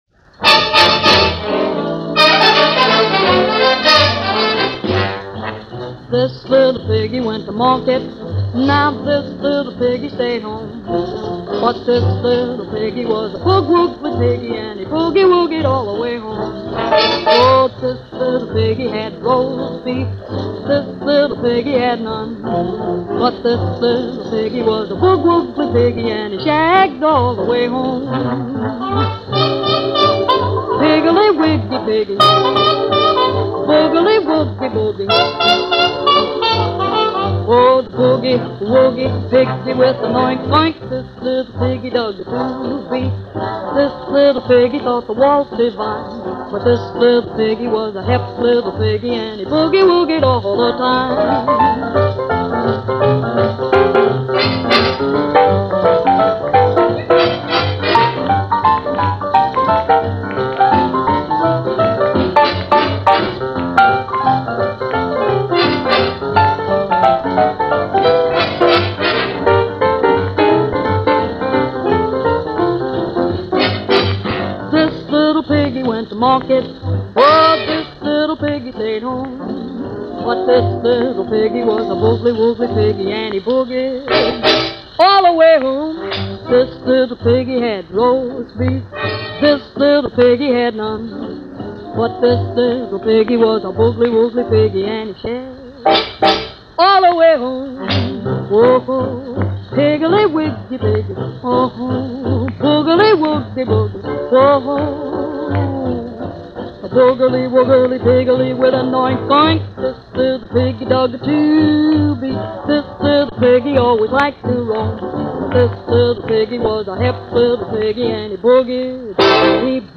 unknown band
Jazz singers